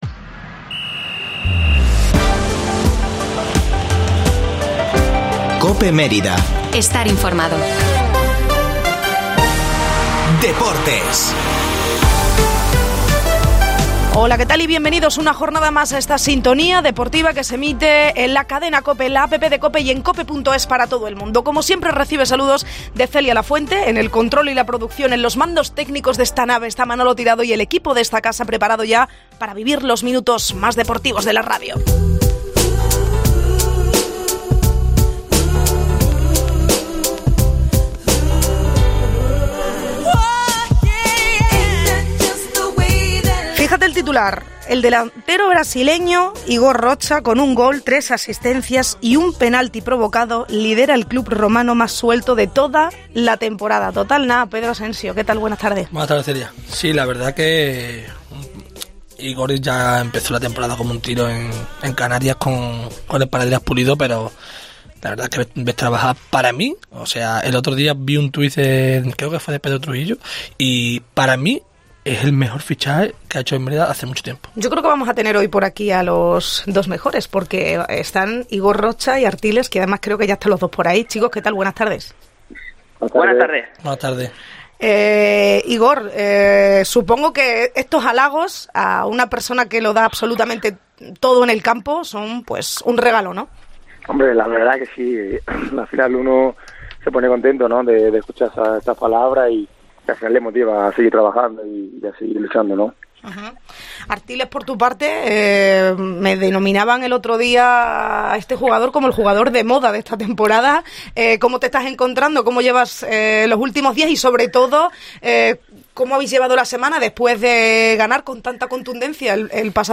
Tertulia del Mérida en COPE